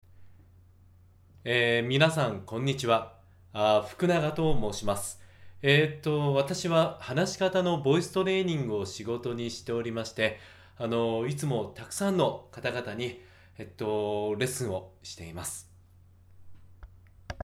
「えー」「あのー」などの口癖が多い自己紹介
とても聞きづらいですし、内容よりも「えー」などの口癖が気になってしまいますね。
しかも、「緊張しているのかな？」「自信がなさそう」という印象を与えてしまいます。